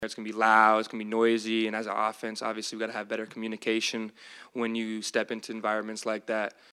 The Gators will be ready for the tenacious crowd, Pearsall said.